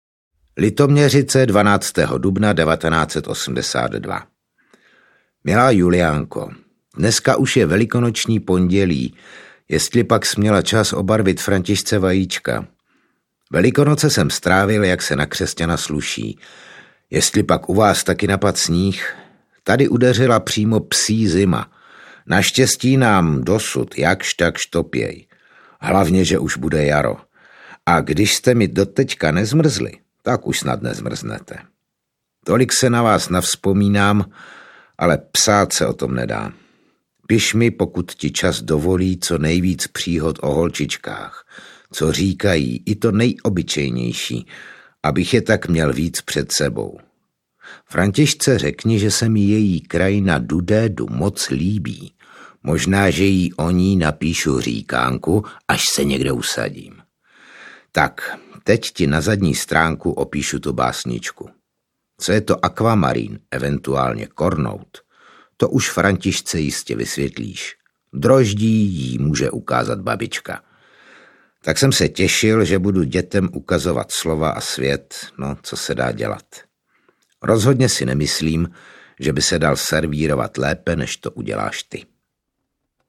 Z veršů, pohádek a dopisů Ivana Martina Jirouse hraje a zpívá Dismanův rozhlasový dětský soubor.
Ukázka z knihy